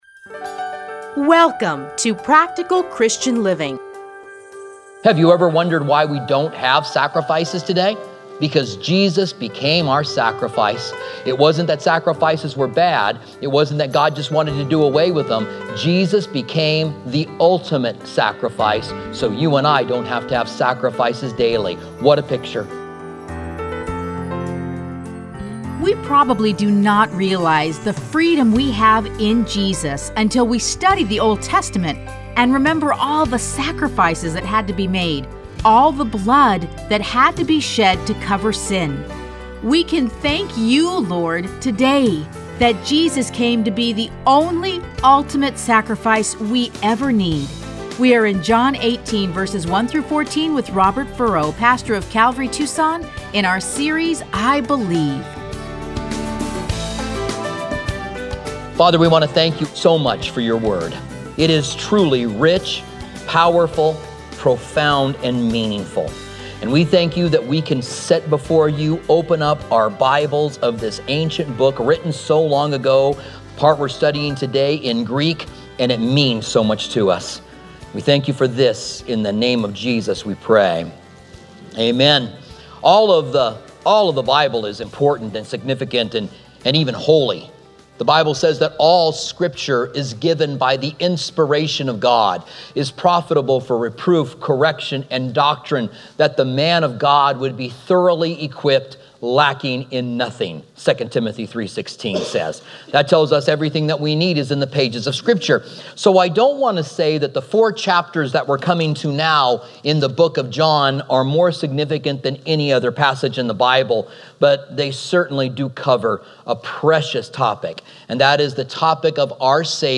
Listen to a teaching from John 18:1-14.